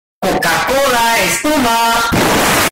Coca Cola Espuma Earrape